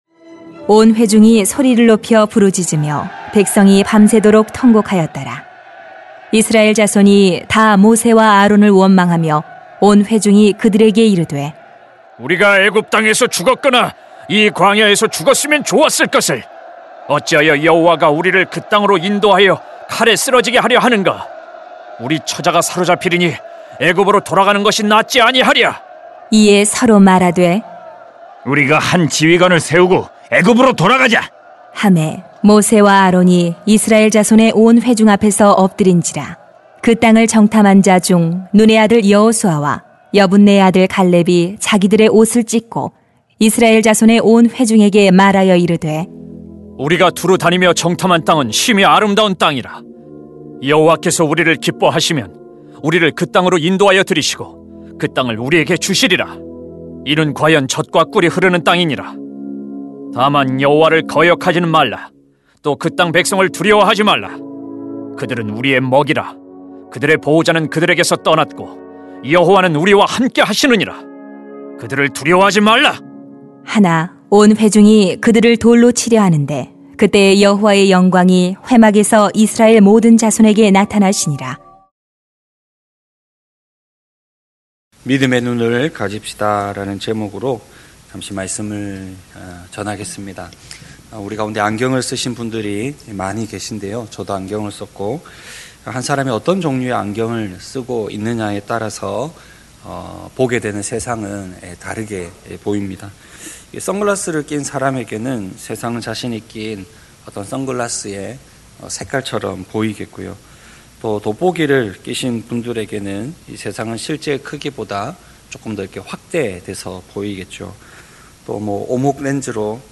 [민 14:1-10] 믿음의 눈을 가집시다 > 새벽기도회 | 전주제자교회